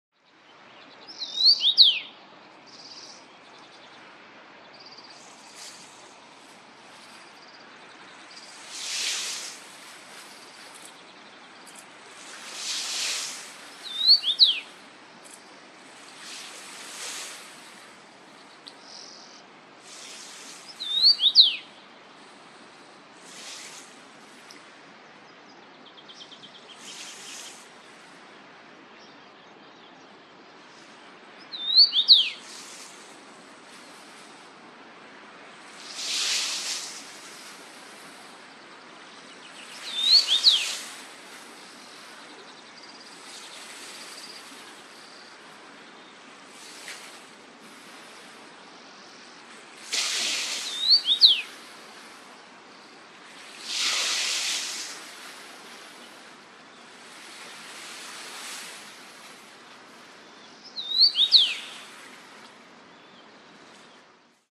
Common rosefinch song Rosenfink sang sound effects free download
About Common rosefinch song Rosenfink sang Mp3 Sound Effect